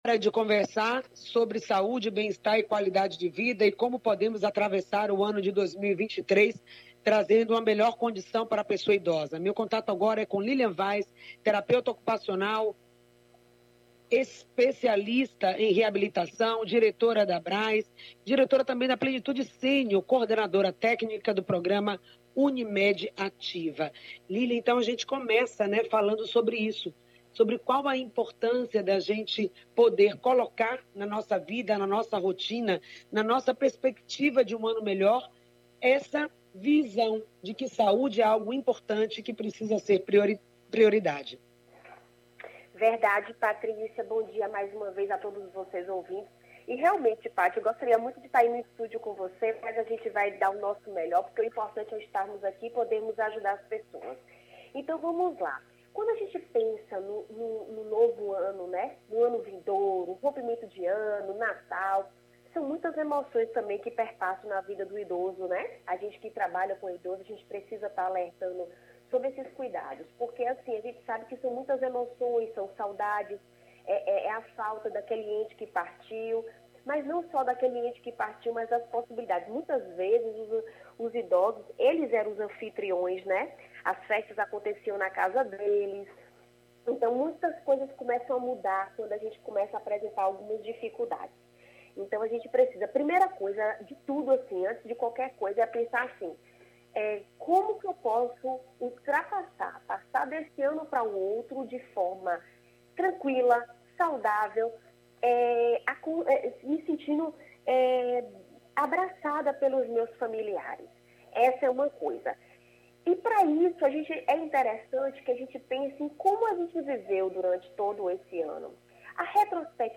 O Programa Em Sintonia, acontece de segunda à sexta, das 9h às 9h55, pela Rádio Excelsior AM 840.